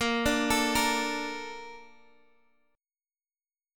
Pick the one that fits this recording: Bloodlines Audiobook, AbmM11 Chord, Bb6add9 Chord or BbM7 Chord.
BbM7 Chord